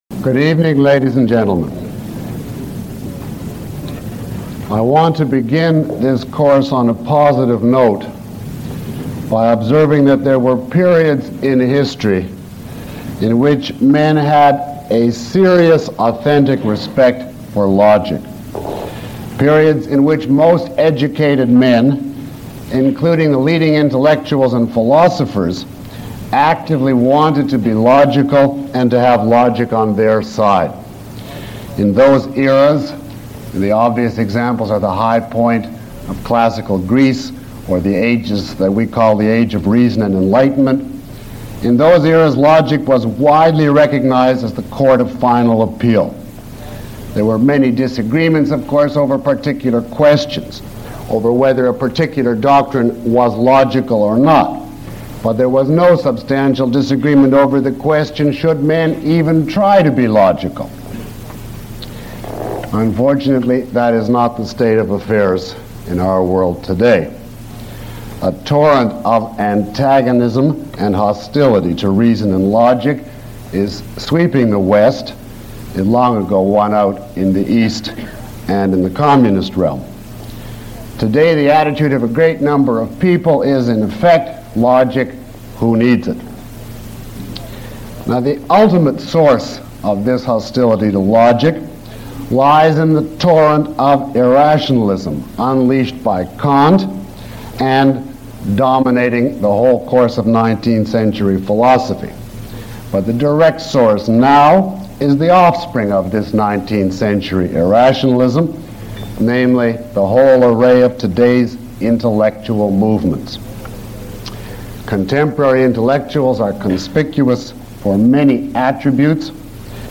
This lecture provides an overview of man’s need for logic and the fundamental rules that guide it.
Download Full Course Study Guide (PDF) Q&A Guide Below is a list of questions from the audience taken from this lecture, along with (approximate) time stamps. 1:45:30 Could you suggest reading material aside from Aristotle? 1:47:57 Many universities do not have a traditional course as you describe it.